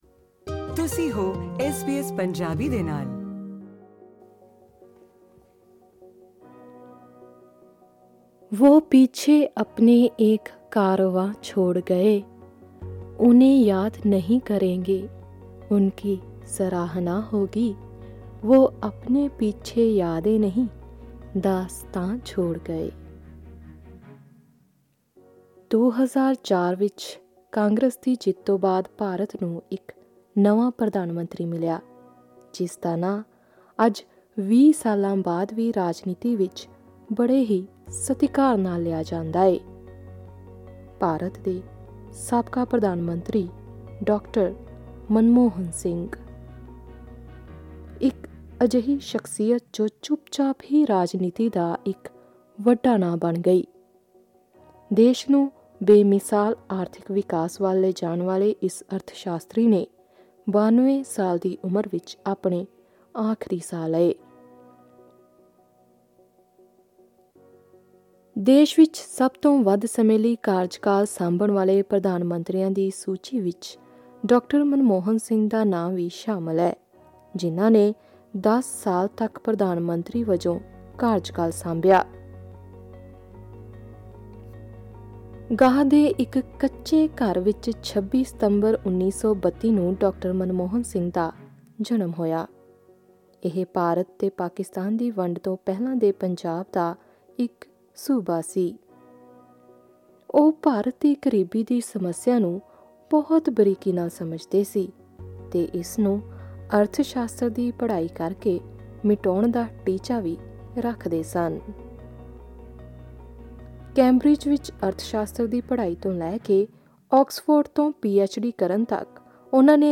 ਐਸ ਬੀ ਐਸ ਪੰਜਾਬੀ ਨਾਲ ਗੱਲਬਾਤ ਕਰਦਿਆਂ ਇਹਨਾਂ ਮੁਲਾਕਾਤਾਂ ਦੀਆਂ ਕੁੱਝ ਯਾਦਾਂ ਸਾਂਝੀਆਂ ਕਰਦਿਆਂ ਉਹਨਾਂ ਦੱਸਿਆ ਕਿ ਡਾ. ਮਨਮੋਹਨ ਸਿੰਘ ਇੱਕ ਬੇਹੱਦ ਇਮਾਨਦਾਰ ਵਿਅਕਤੀ ਸਨ।